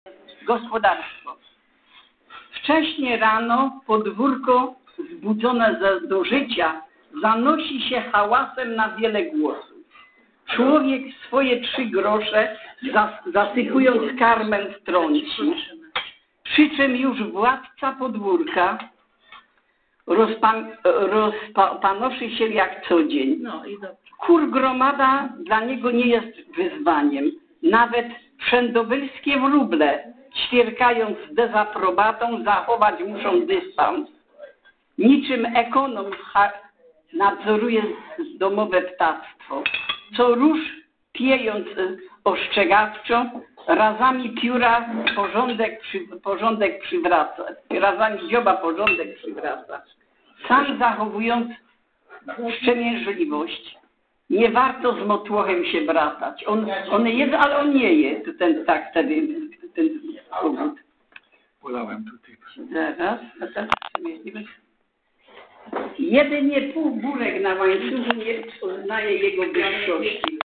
w siedzibie TMZŻ